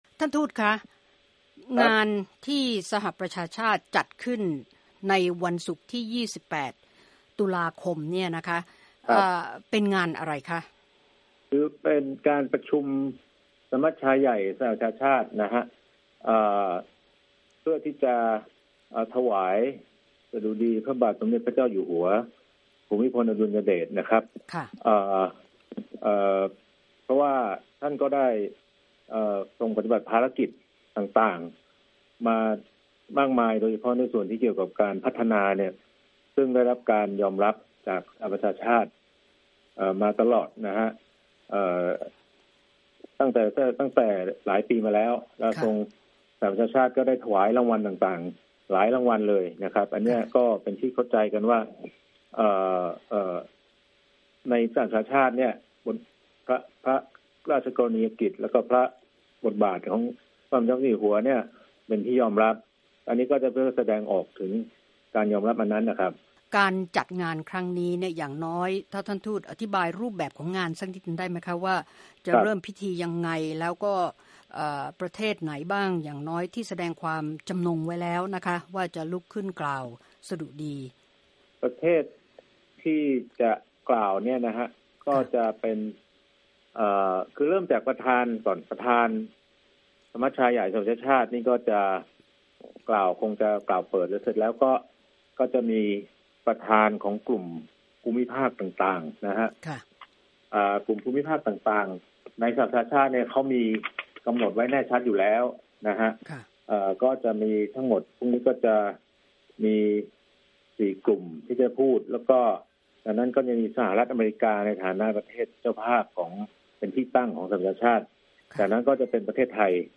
Thai UN Ambassador Interview